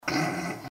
Snort